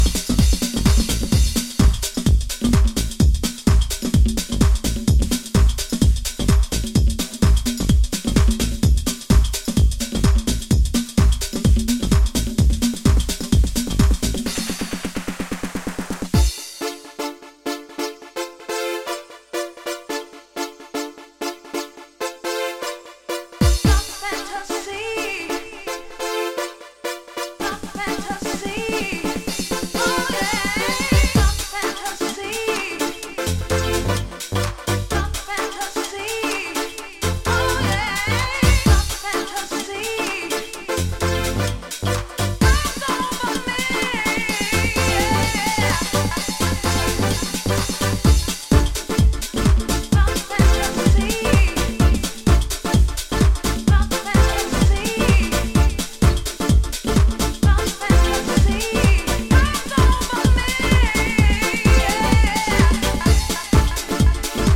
- исходная фонограмма: